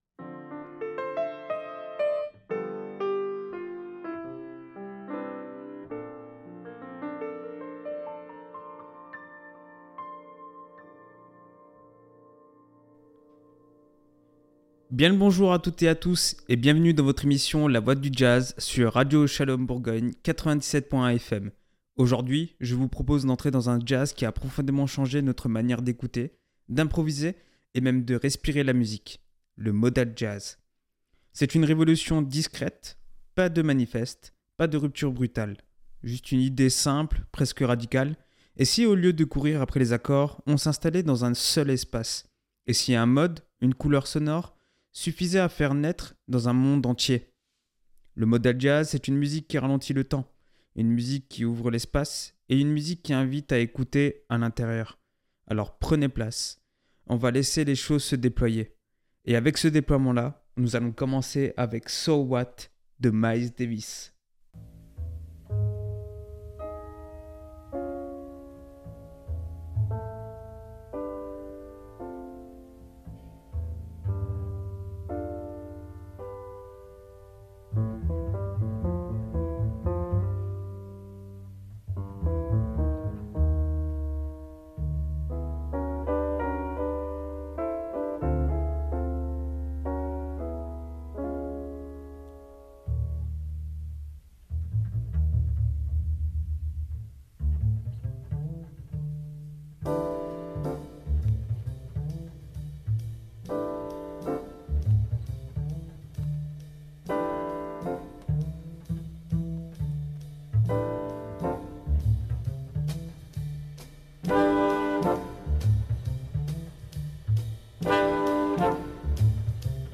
Le Modal Jazz, c’est une musique qui ralentit le temps.